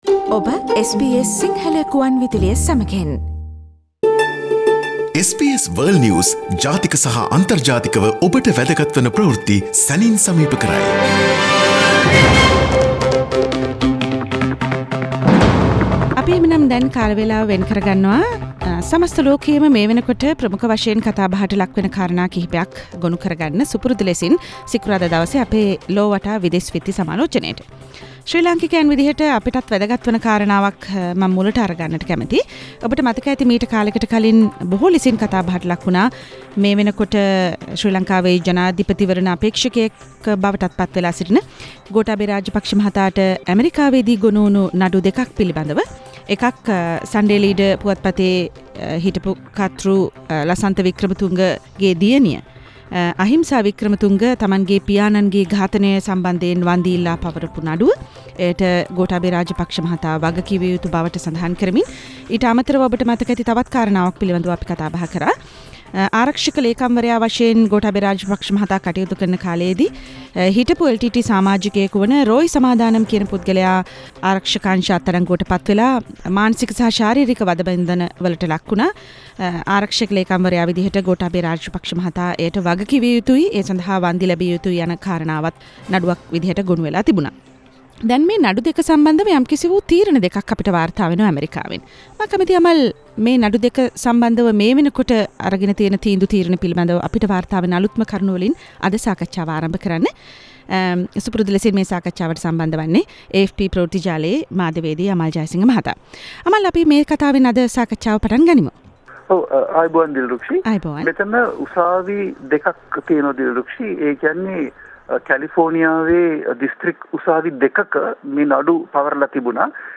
"ලොව වටා" විදෙස් විත්ති සමාලෝචනය - සෑම සතියකම SBS සිංහල ගුවන් විදුලියේ සිකුරාදා වැඩසටහනින්